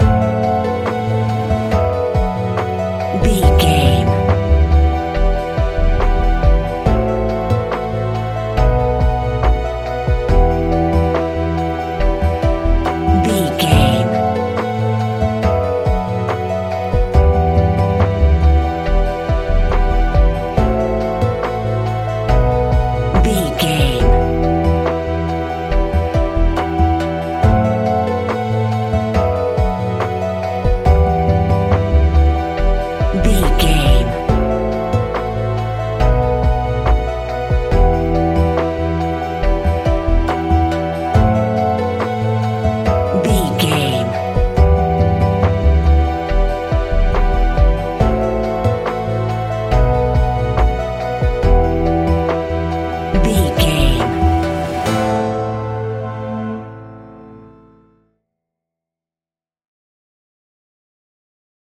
Ionian/Major
laid back
sparse
new age
chilled electronica
ambient
atmospheric